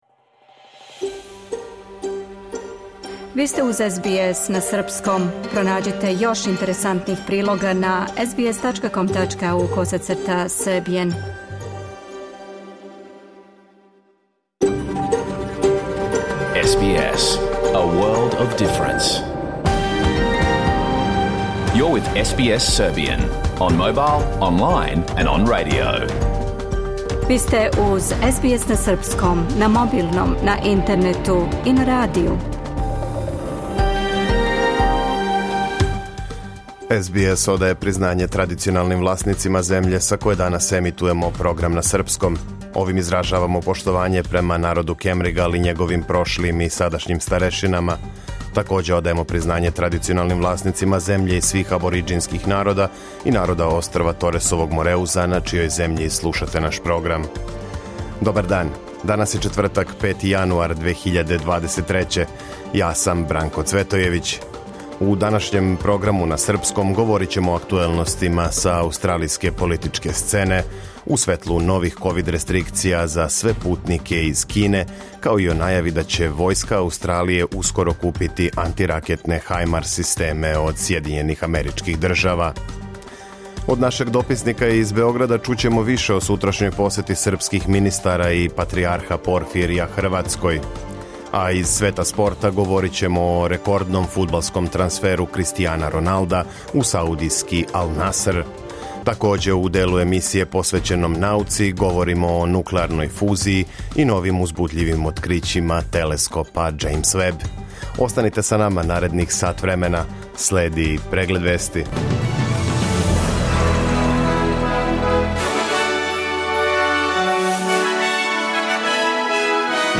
Serbian News Bulletin Source: SBS / SBS Serbian